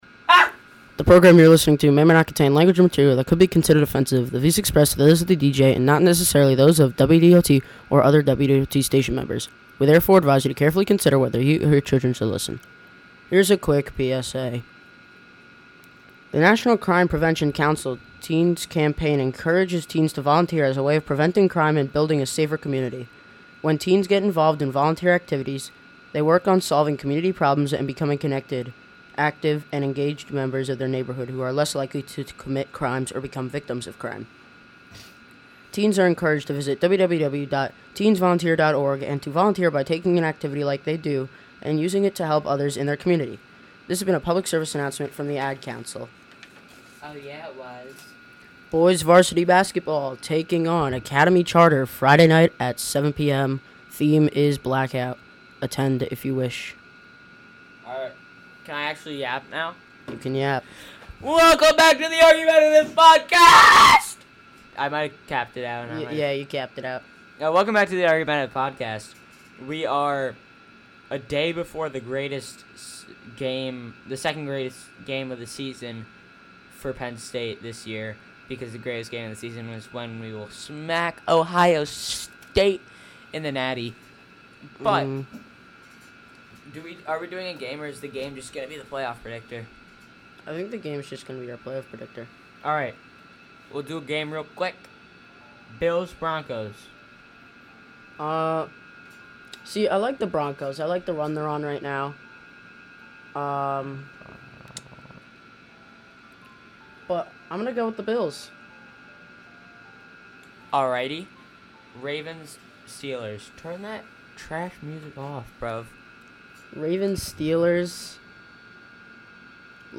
Click here to listen This on-demand broadcast does not violate the US Copyright Law. Music used is incidental or background clips, in accordance of 37 CFR 380.2. of the US Copyright Law.